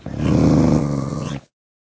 growl1.ogg